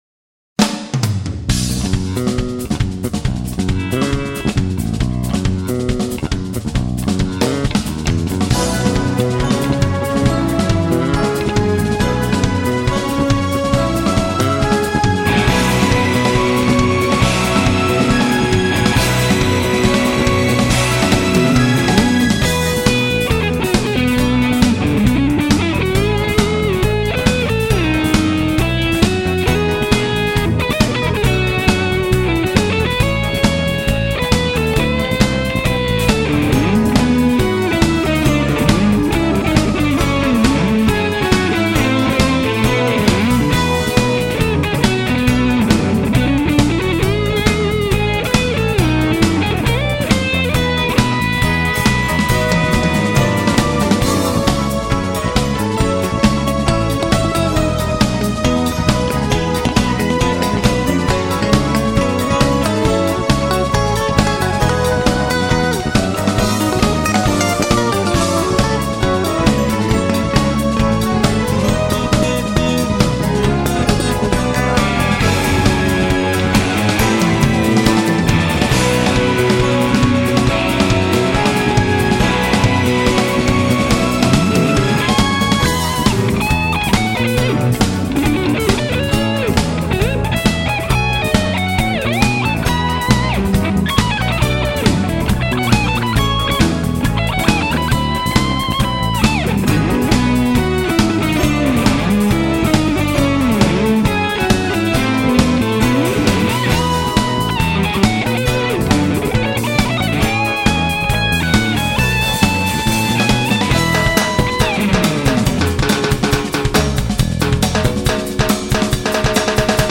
멜로뒤가 좋네요